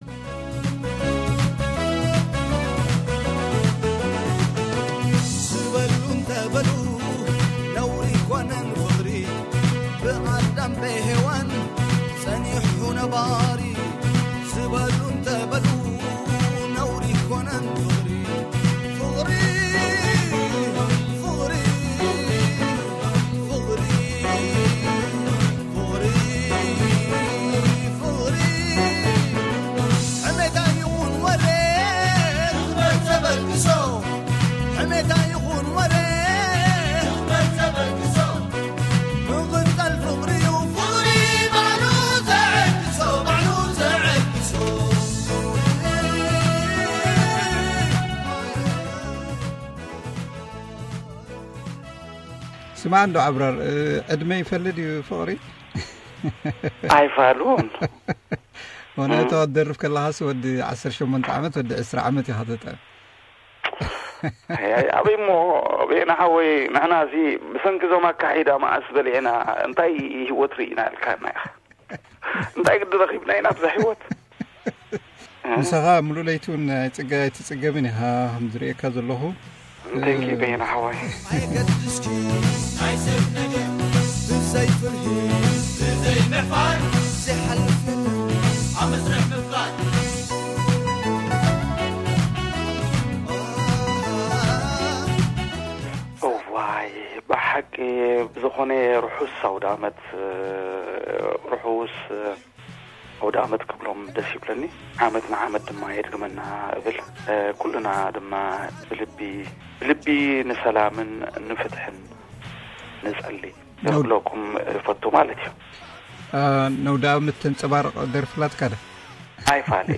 Abrar Osman Interview Part 3